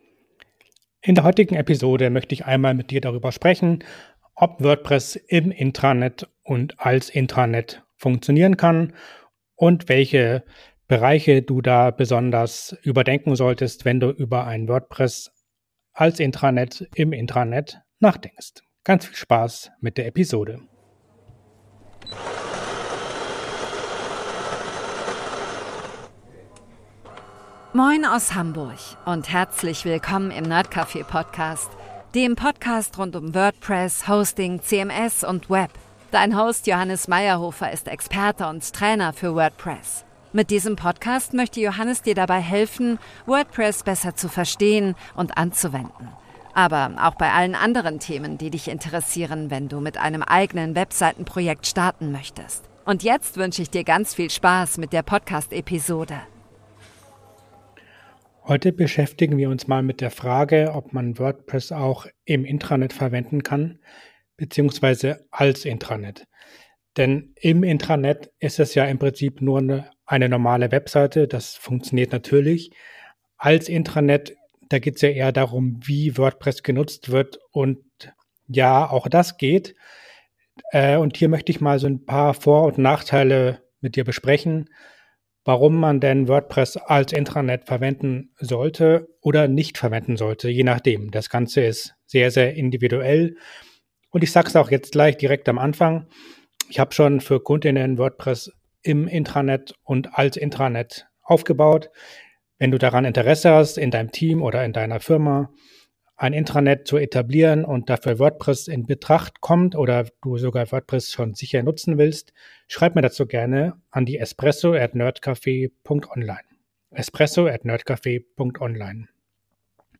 nerdcafe Podcast steht für ein offenes, vielfältiges und zugängliches Internet – verständlich erklärt, entspannt im Ton, aber mit Tiefgang und verschiedenen Expert*innen in jeder zweiten Episode.